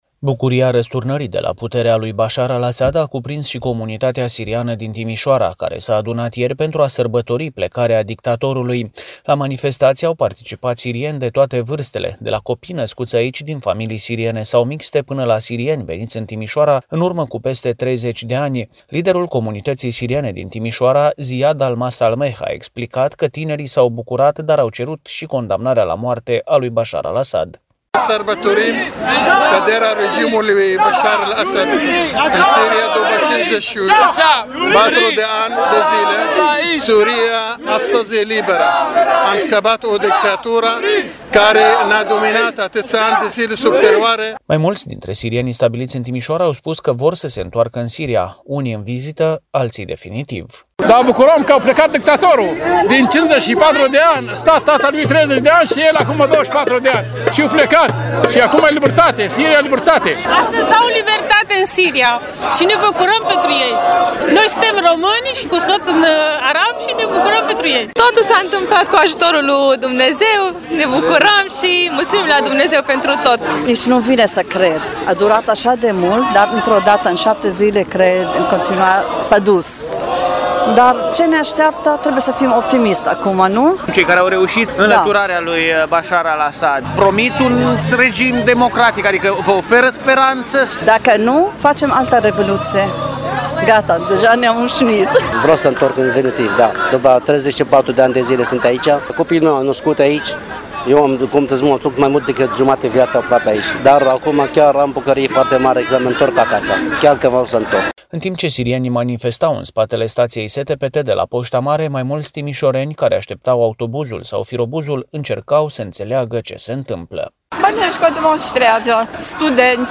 sirienii au scandat împotriva lui Basahar Al Asad și au afișat steagurile țării de origine.